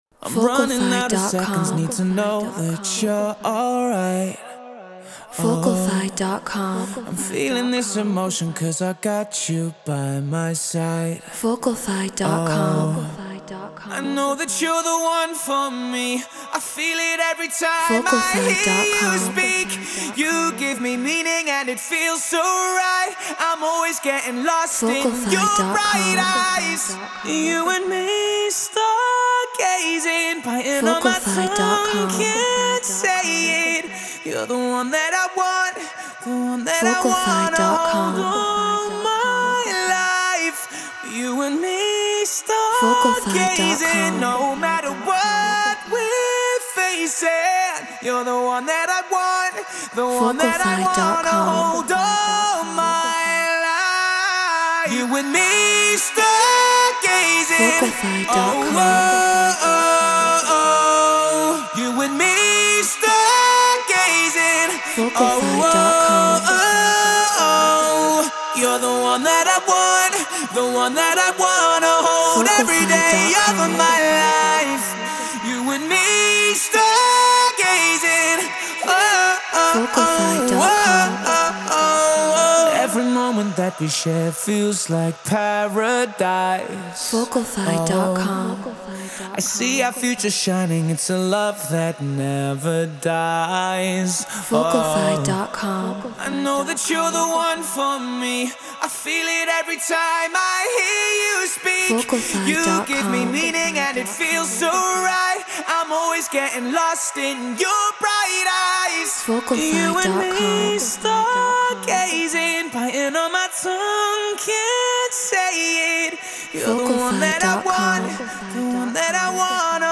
Future Bass 140 BPM Emaj
Neumann TLM 103 Apollo Twin X Pro Tools Treated Room